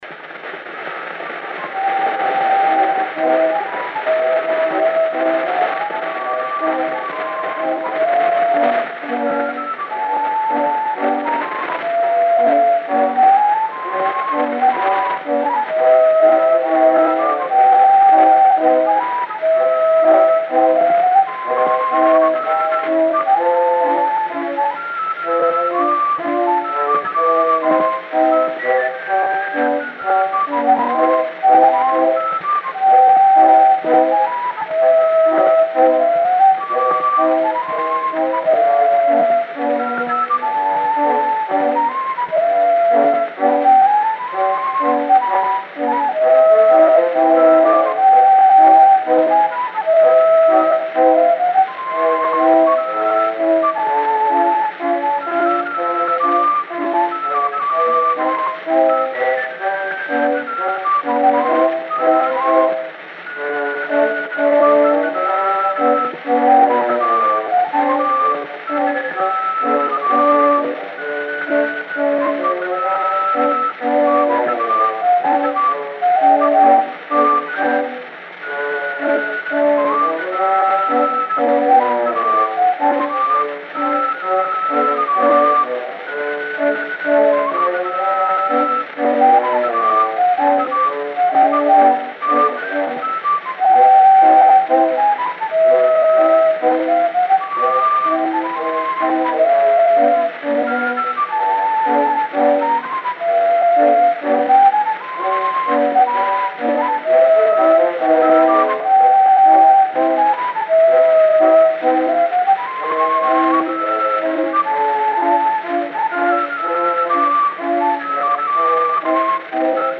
O gênero musical foi descrito como "Schottisch"